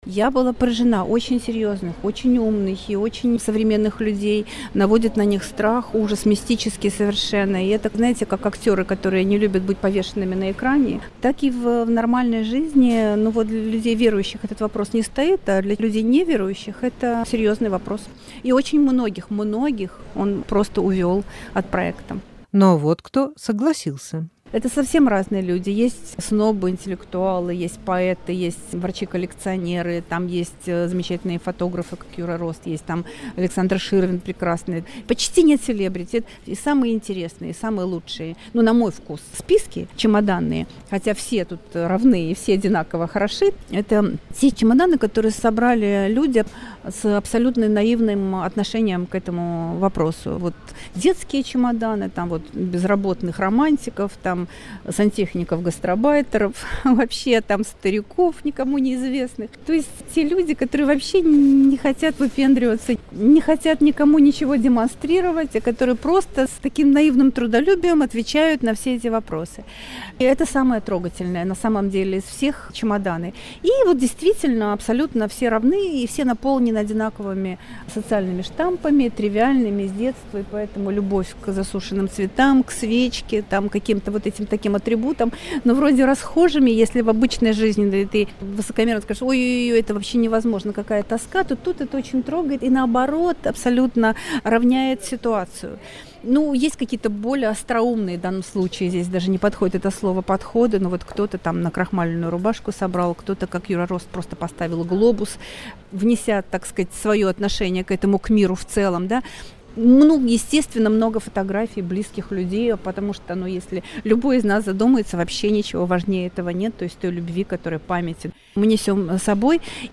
Искусствовед Марина Лошак о выставке "Мой самый важный чемодан"